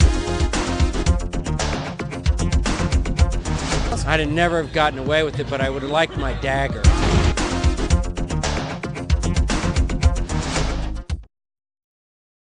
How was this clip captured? LA Premiere of The Lord of the Rings: The Two Towers (RealPlayer format)